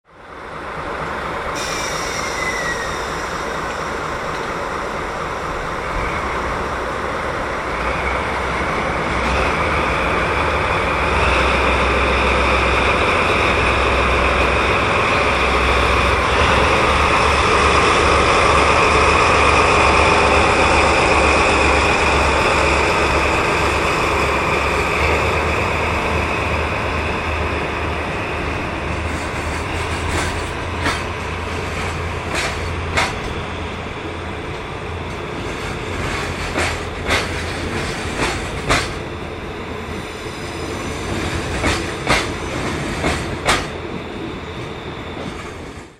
四日市港周辺の工場との物流を担う貨物ターミナルとなっており、ディーゼル機関車牽引による貨物列車が発着します。
DD51牽引貨物列車(46秒・902KB)